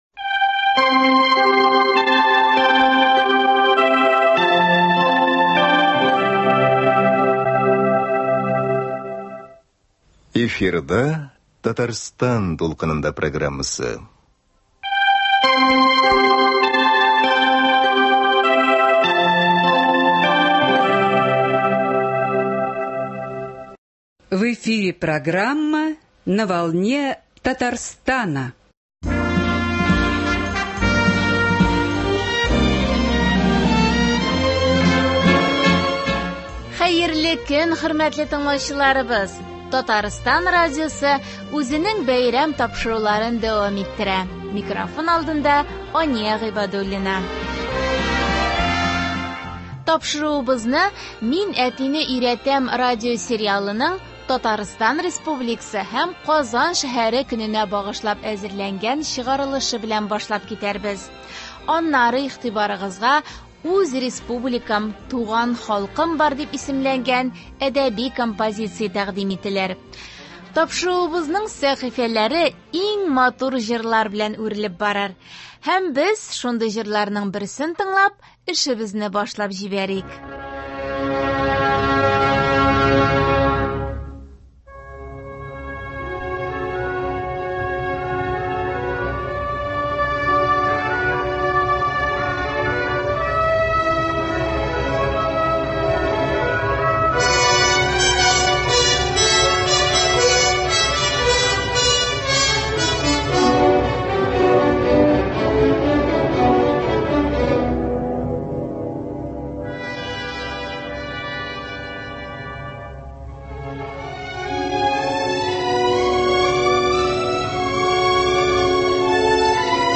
Әдәби-музыкаль композиция.